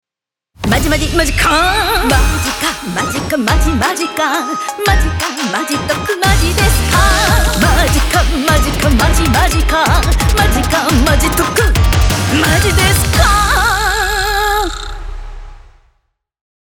TVCM
SONG
Vocal: